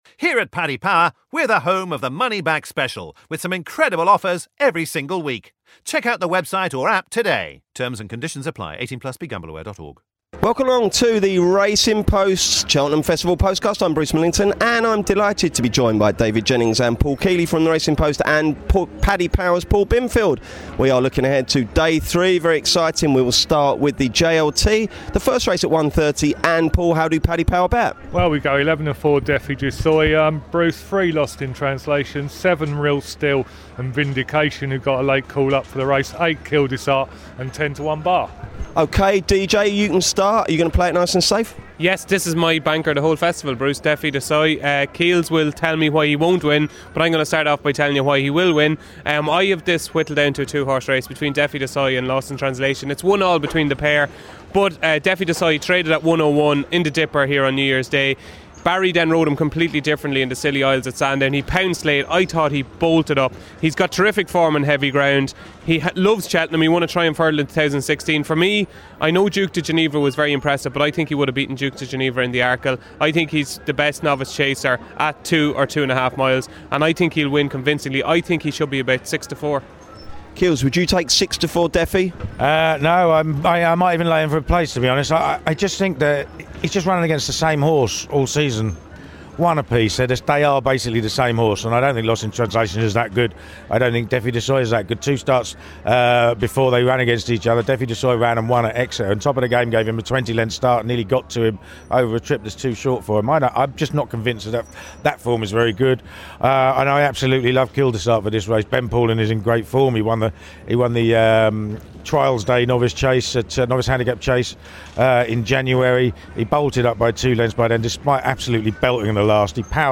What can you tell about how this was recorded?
On the show: - The lads take shelter in the Shopping Village as Storm Gareth blows gently over Cheltenham Racecourse, but with racing getting the all clear there are lots of tips to get on.